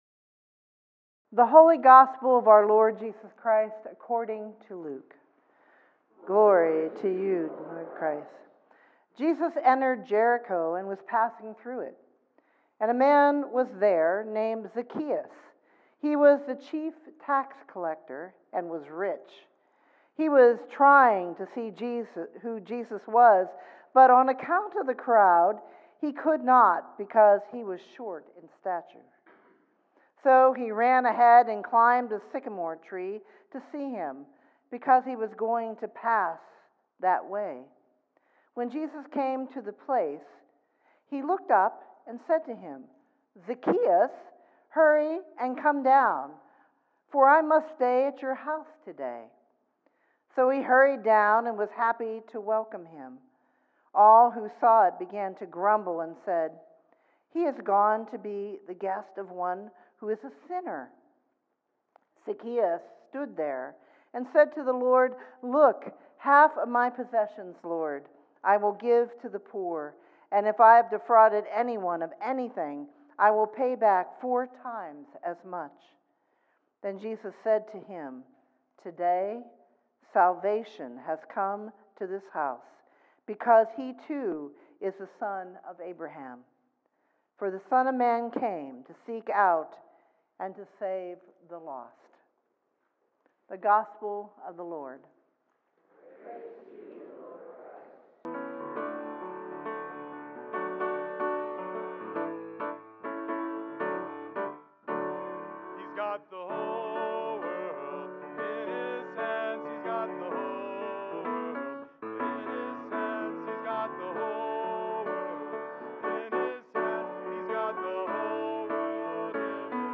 All Saints' Day